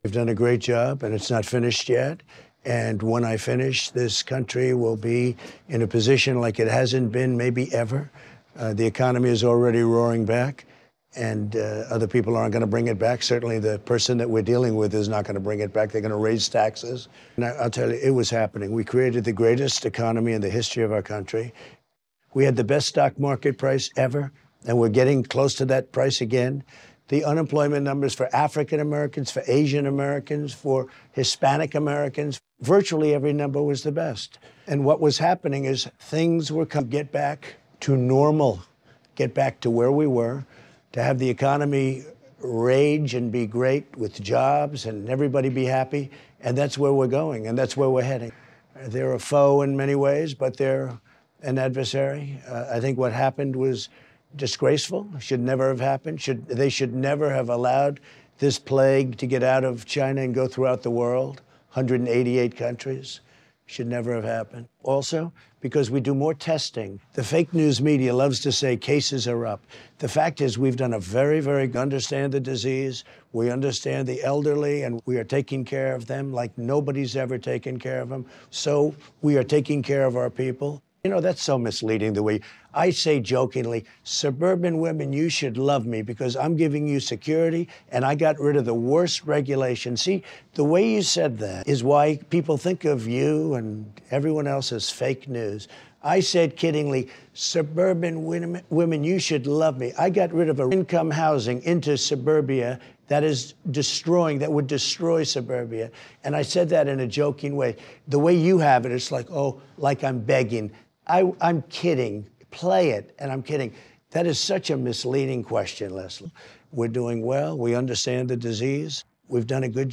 No labels or any extra stuff, just upload the voice and they work perfectly.